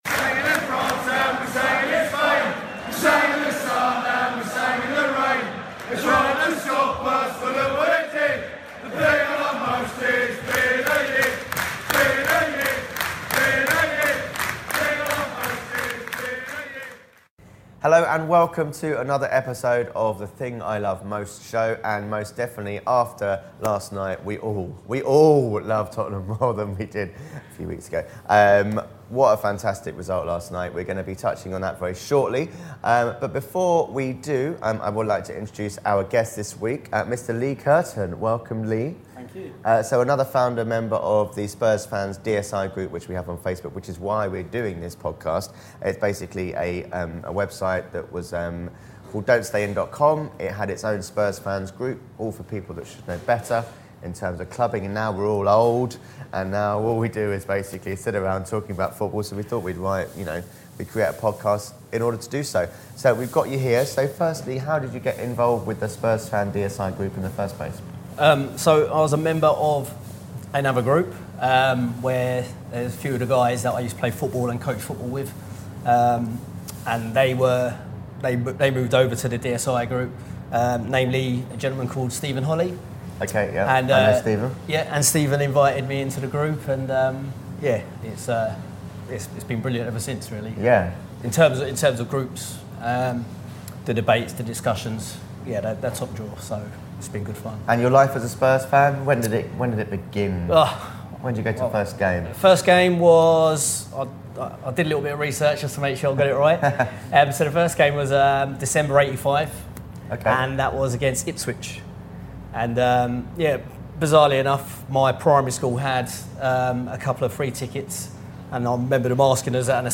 in the studio
on video call